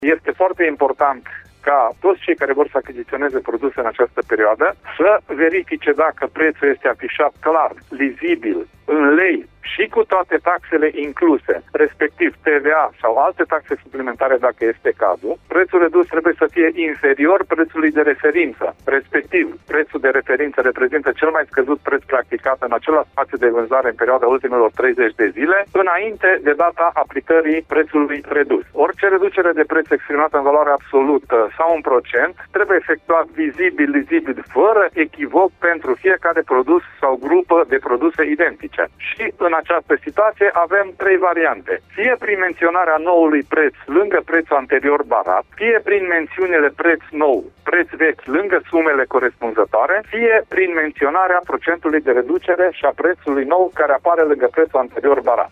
invitat astăzi la Radio Cluj